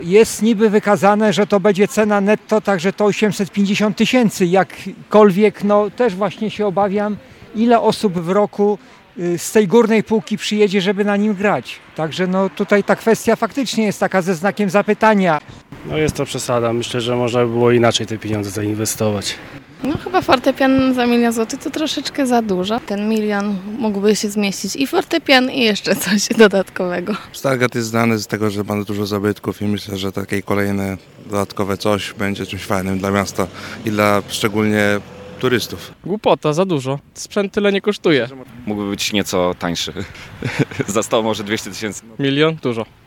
STAR-Sonda-Fortepian.mp3